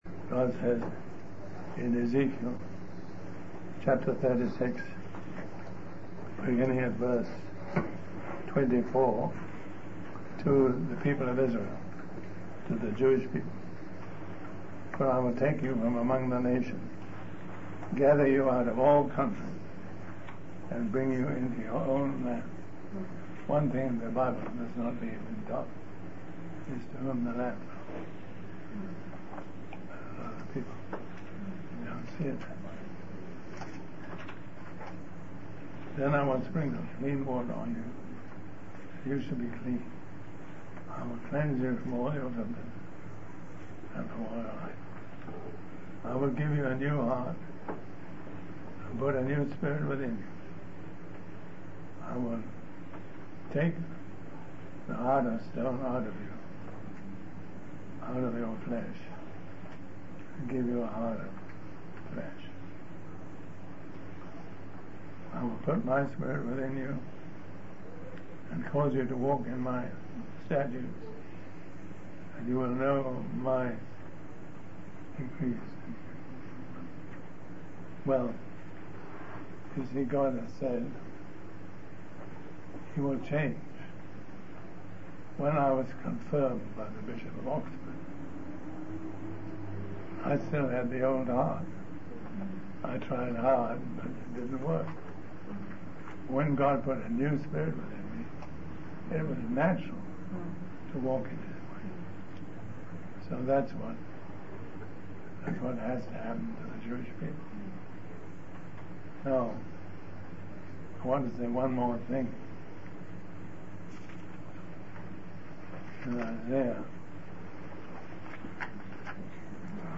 In this sermon, the speaker discusses the importance of recognizing the severity and kindness of God. He warns that just as God did not spare the natural branches (referring to Israel), He will not spare those who continue in sin. The speaker emphasizes the need for believers to continue in God's kindness to avoid being cut off.